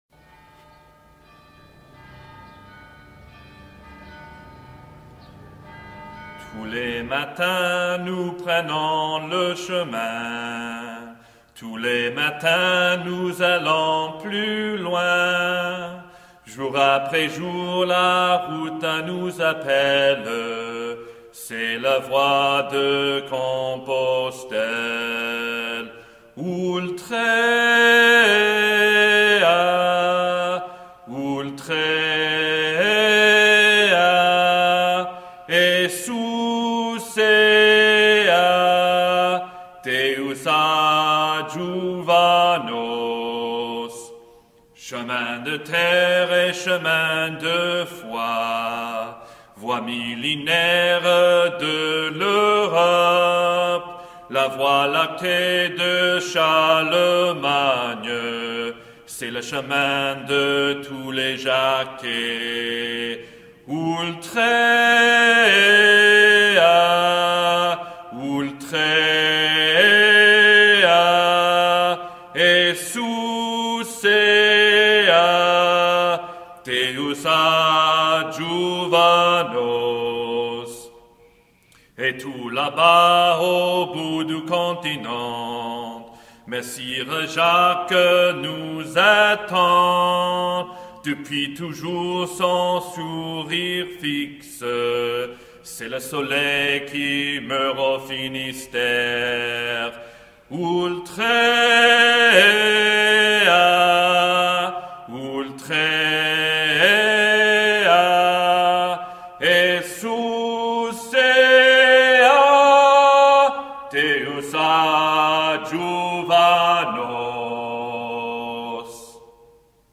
canti_pellegrini.mp3